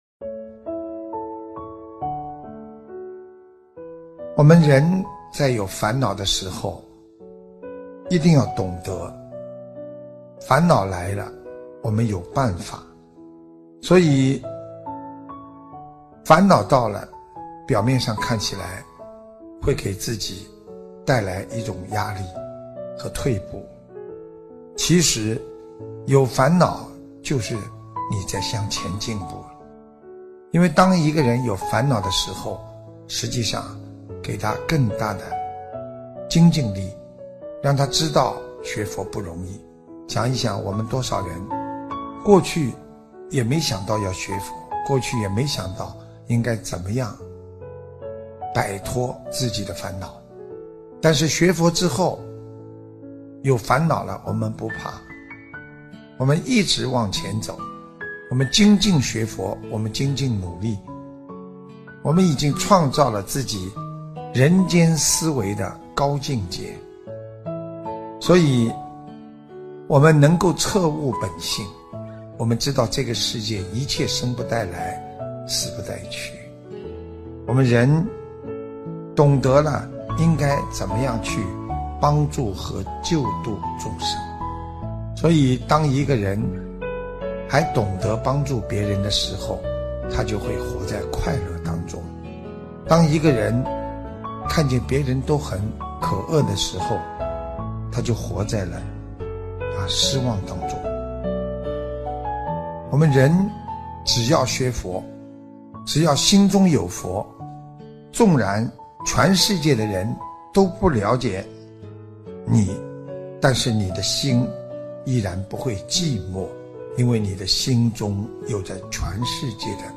视频：101.烦恼即菩提！2016新加坡 - 法会节选 心灵净土